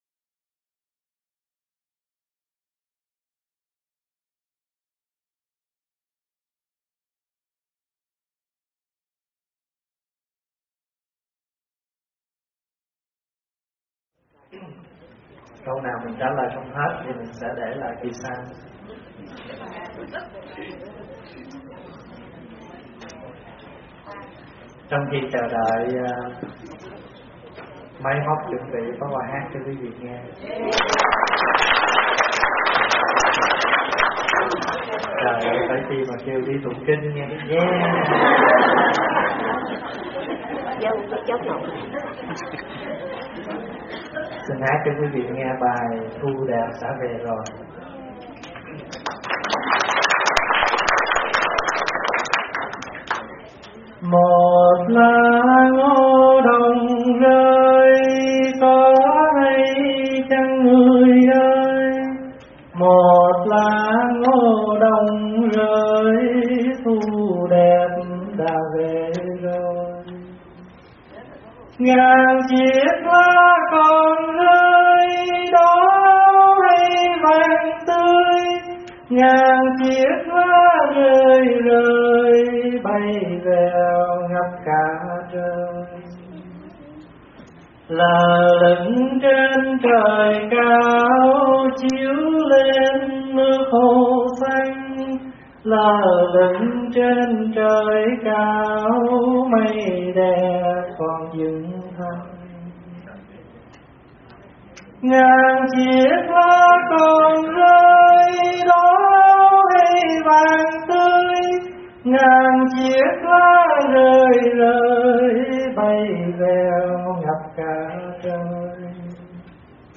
Mp3 Trả Lời Vấn Đáp 2013 - ĐĐ.